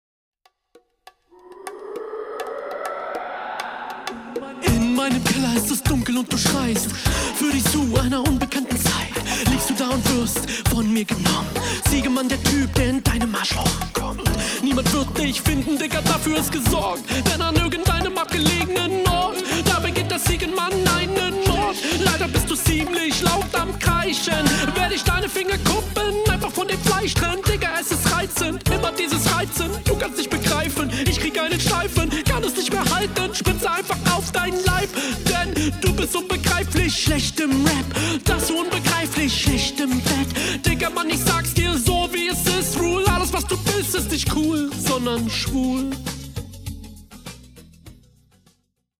Flow im takt, holt mich aber nicht ab. konzept nicht vorhanden. soundbild erfüllt seinen zweck.